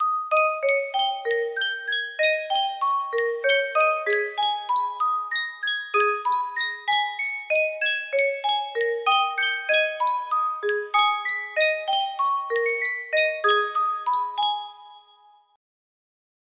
Sankyo Music Box - Ivanovici Donauwellen D music box melody
Full range 60